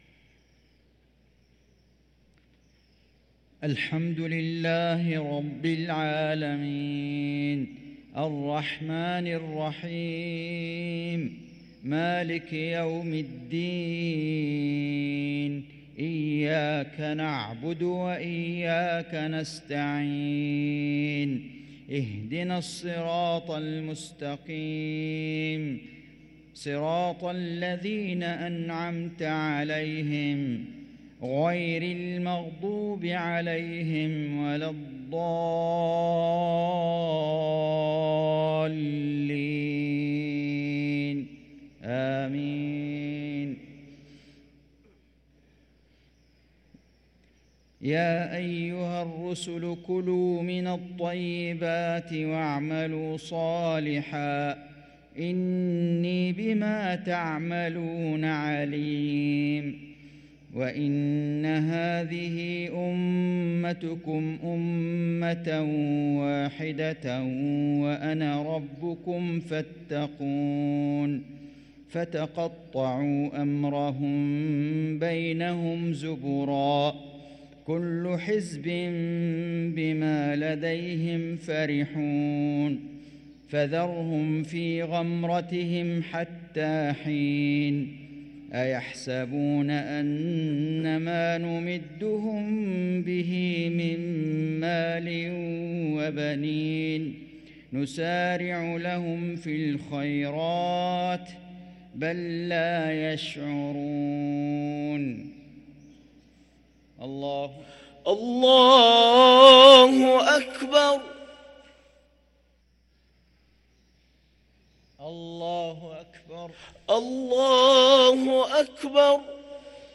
صلاة المغرب للقارئ فيصل غزاوي 25 شعبان 1444 هـ
تِلَاوَات الْحَرَمَيْن .